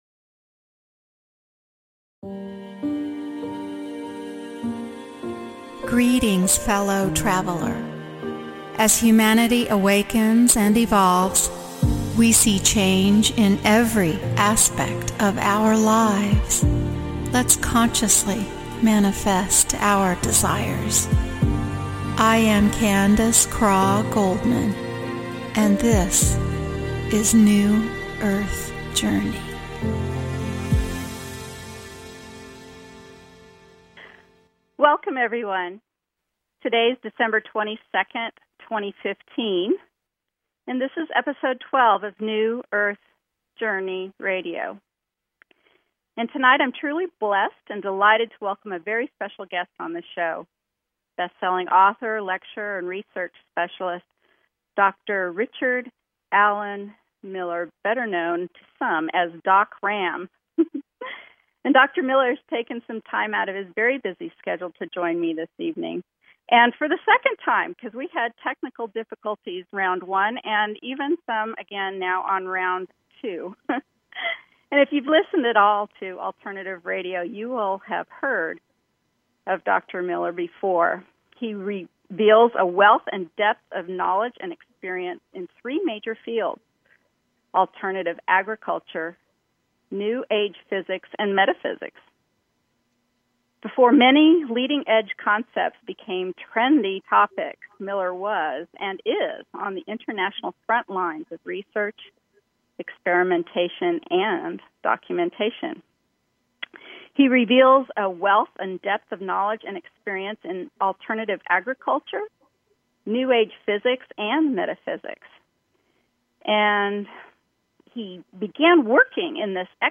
New Earth Journey interview